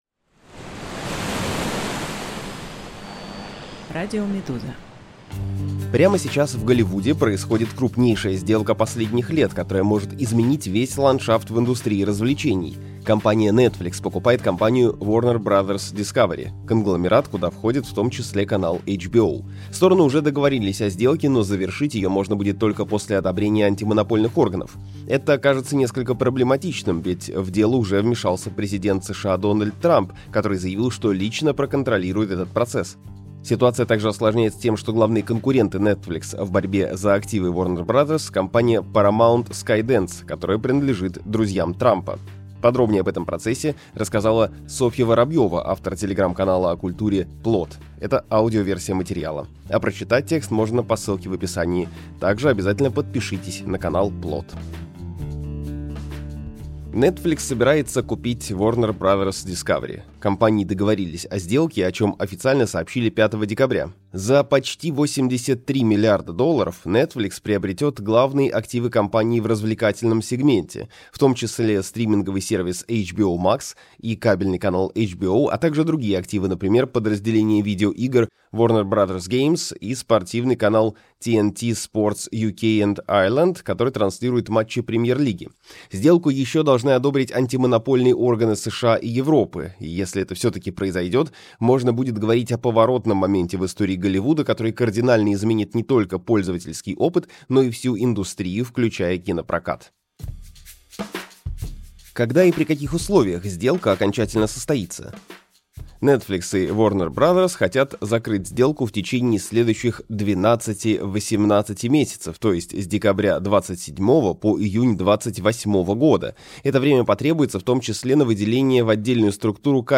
Аудиоверсию этого текста слушайте на «Радио Медуза» подкасты Netflix покупает компанию Warner Bros.